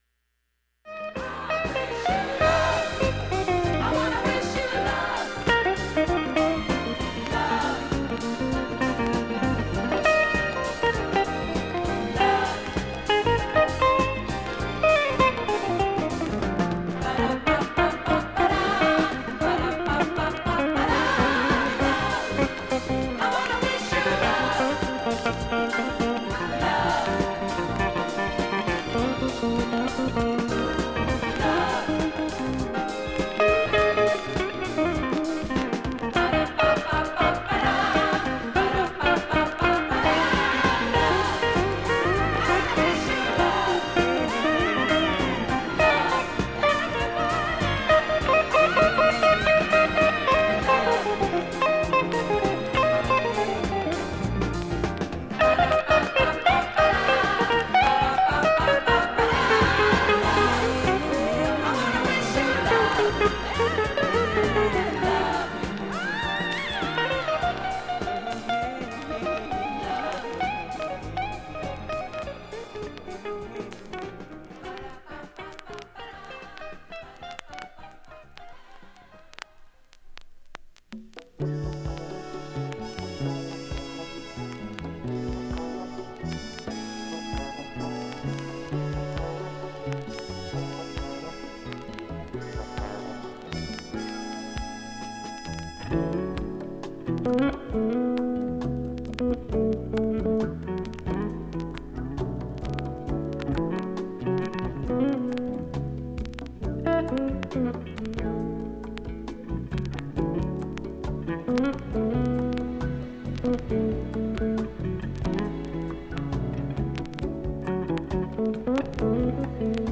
音質良好全曲試聴済み。
のスレ３－４本で4分30秒間チリプツ出ます
ですが１分ほどでかすかになります。
後半は単発周回プツのみになります。
60秒の間に周回プツ出ますがかすかです。
２０回のかすかなプツが１箇所
単発のかすかなプツが5箇所
メロウジャズファンク